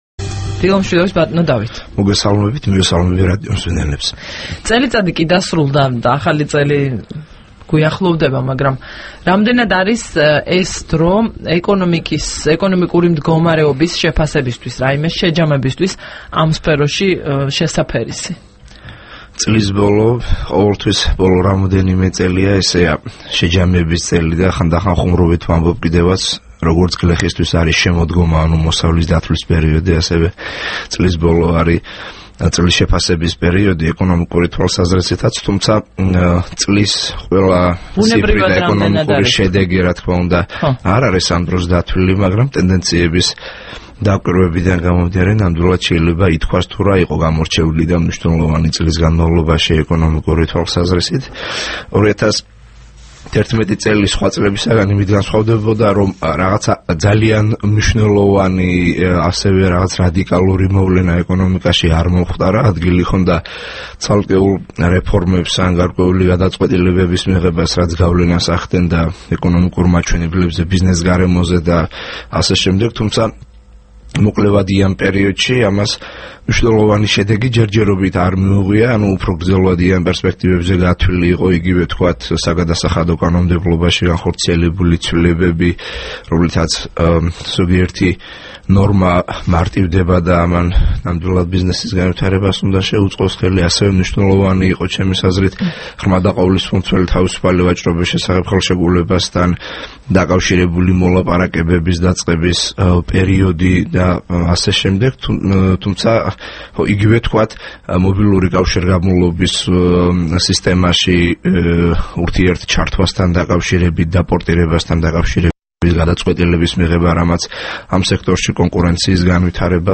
30 დეკემბერს რადიო თავისუფლების დილის გადაცემის სტუმარი იყო დავით ნარმანია, კავკასიის ეკონომიკური და სოციალური კვლევითი ინსტიტუტის (CIESR) აღმასრულებელი დირექტორი.